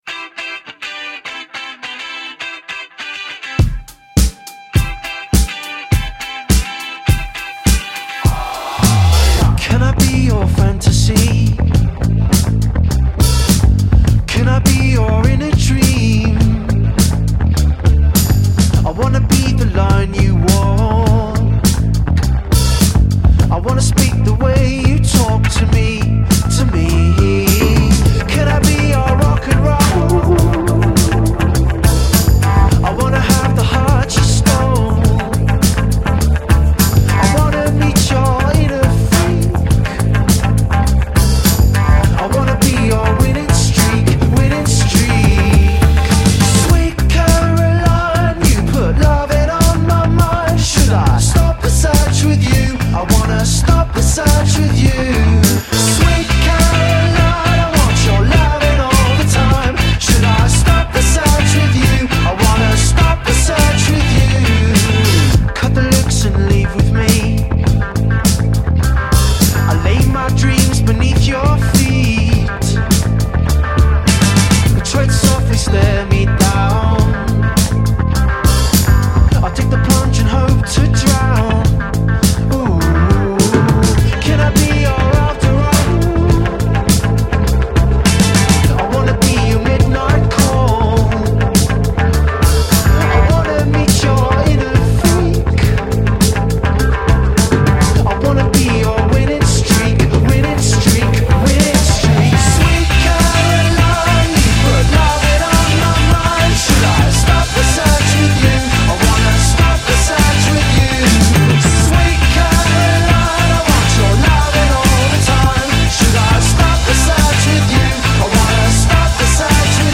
indie rock trio